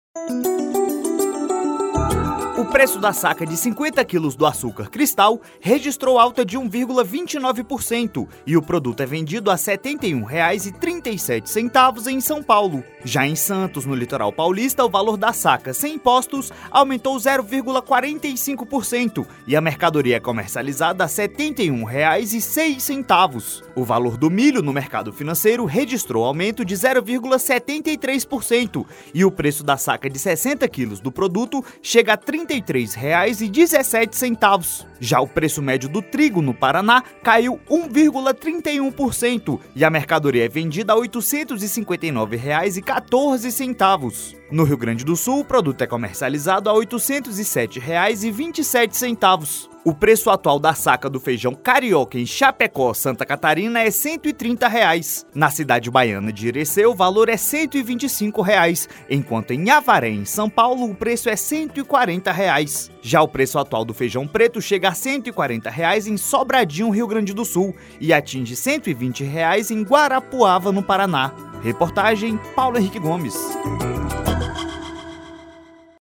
*Reportagem com áudio
reportagem.mp3